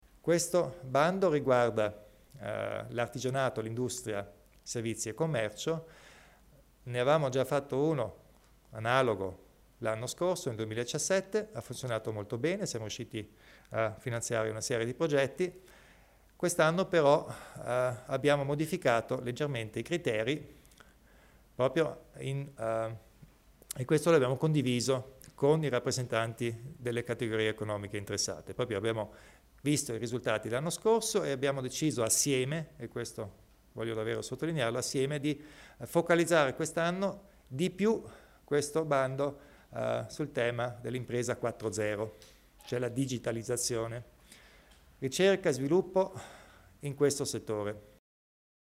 Il Presidente Kompatscher illustra i criteri di assegnazione dei contributi alle piccole imprese